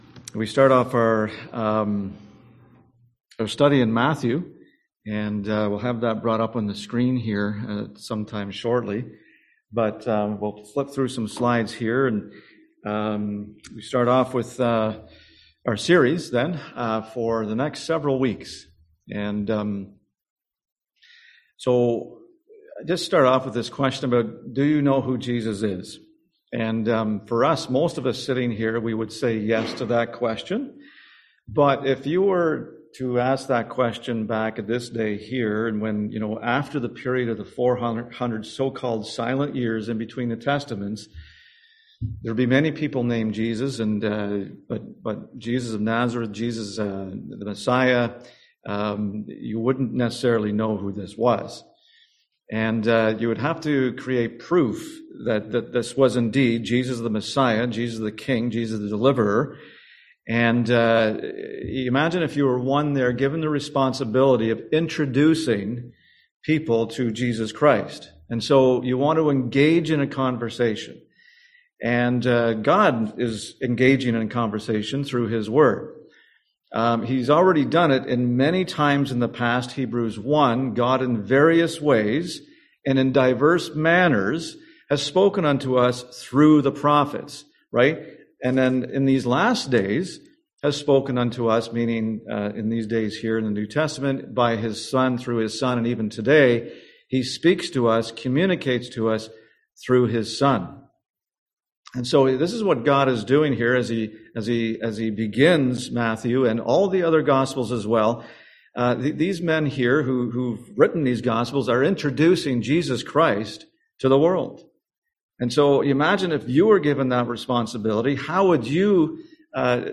Series: Matthew 2022 Passage: Matthew 1 Service Type: Sunday AM